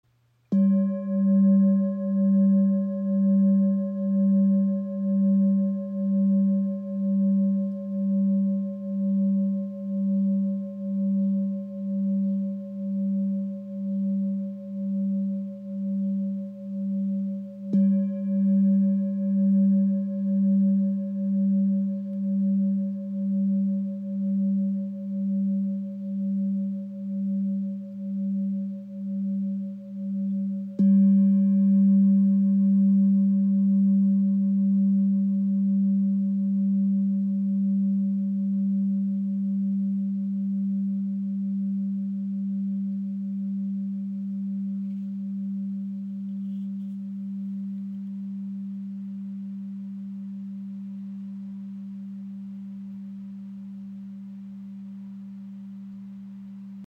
Tibetische Klangschale 19.5 cm – klarer Ton G aus Nepal • Raven Spirit
Klarer, erdender Ton G – ideal für Meditation, Klangarbeit und achtsame Stille.
Klangbeispiel
Ihr obertonreicher Klang im Ton G ist klar und erdend.
Tibetische Klangschale mit Blume des Lebens | ø 19.5 cm | Ton ~ G 432 Hz